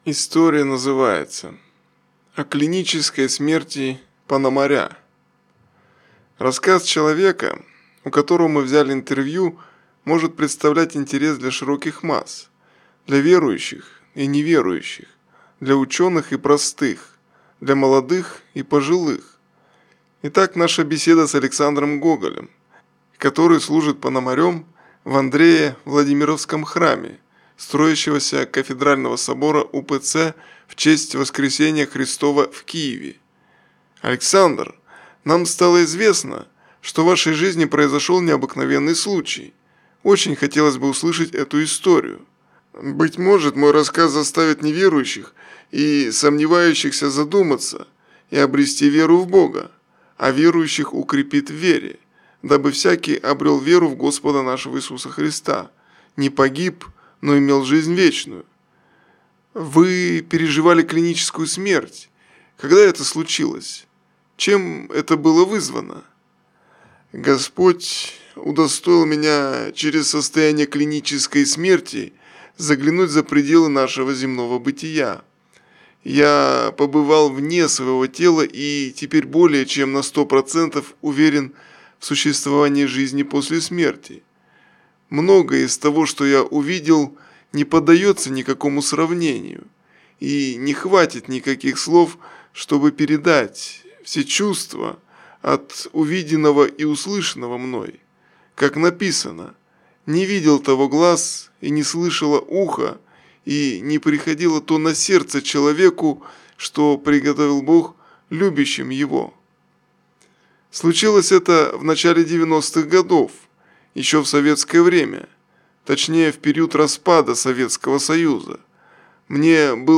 Рассказ человека, у которого мы взяли интервью, может представлять интерес для широких масс, для верующих и неверующих, для ученых и простых, для молодых и...